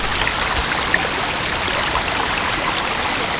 Free Sound Effects
Babblingbrook.mp3